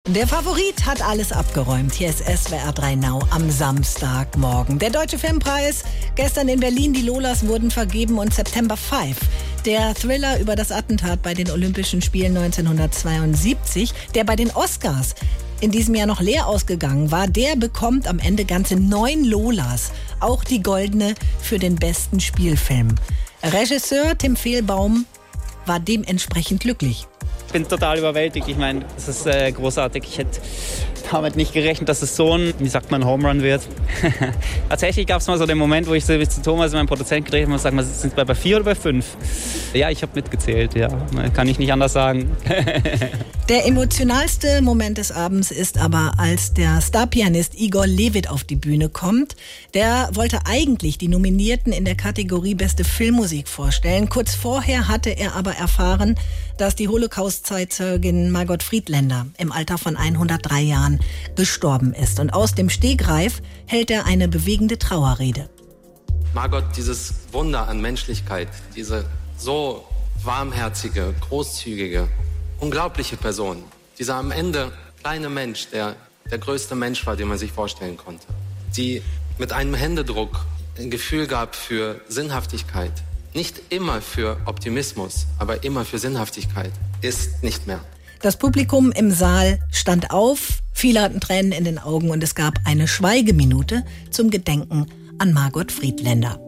Am Freitagabend wurde der Deutsche Filmpreis 2025 verliehen. Für den wohl emotionalsten Moment des Abends sorgte eine Trauerrede von Pianist Igor Levit für Margot Friedländer.